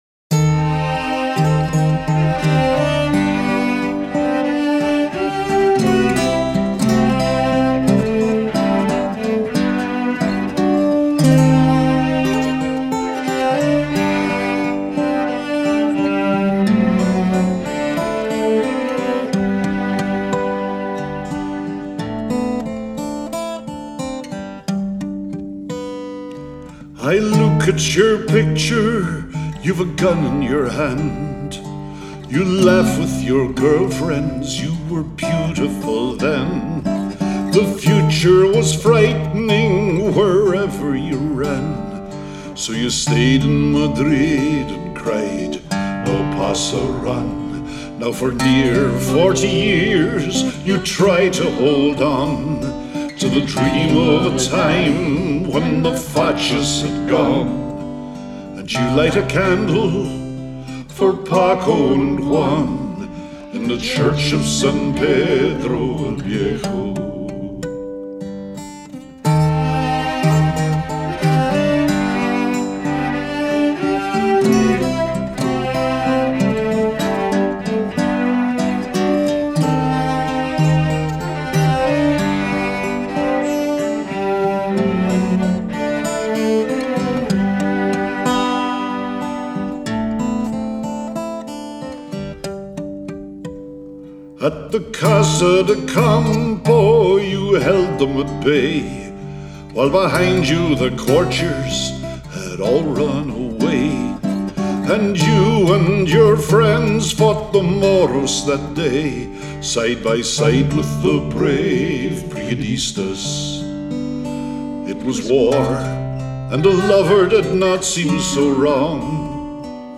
Rhythmically, it’s mostly regular.
The rhythm is that of an entirely-suitable slow tango.